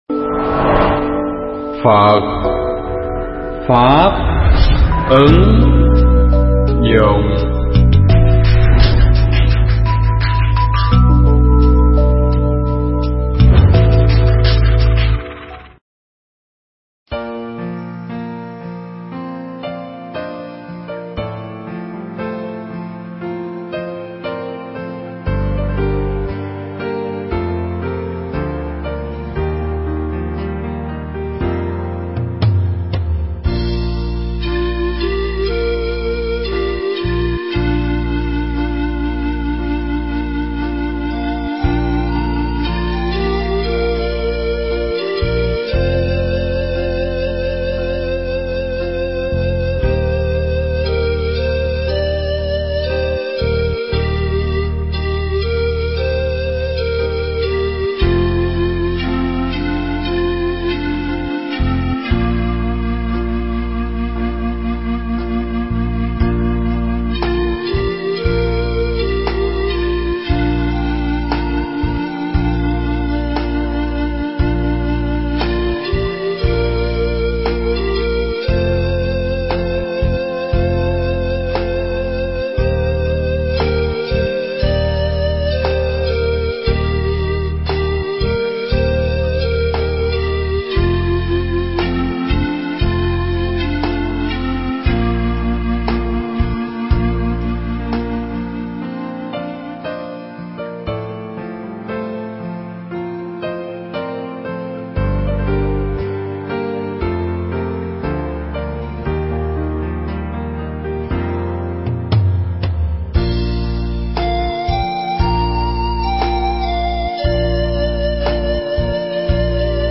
Thuyết pháp Lòng Chung Thủy